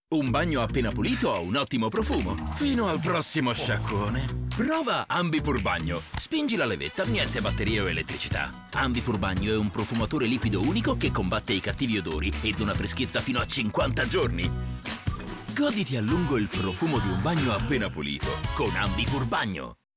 SPOT TV